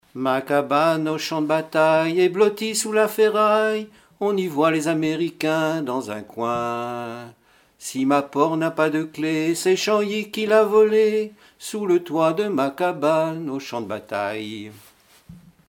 Mémoires et Patrimoines vivants - RaddO est une base de données d'archives iconographiques et sonores.
formulettes enfantines, chansons en français et en breton
Pièce musicale inédite